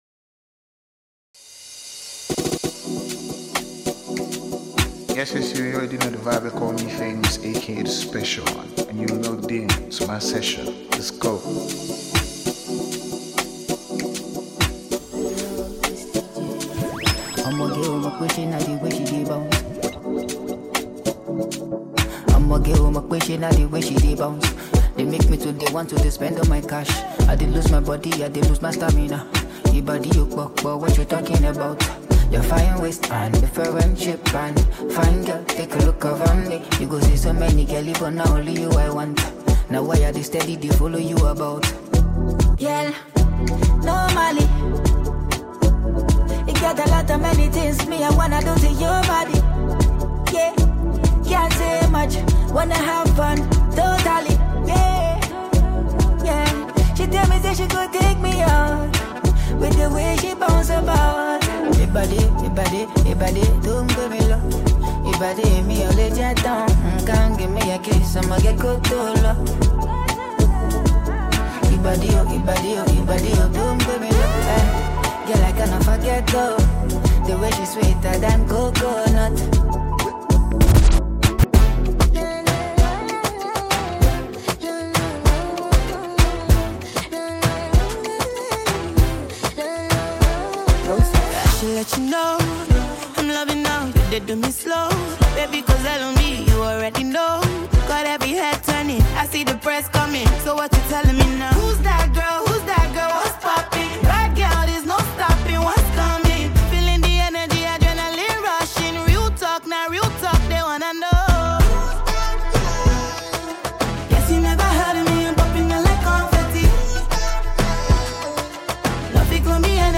this new DJ mix consist of trending new hit songs.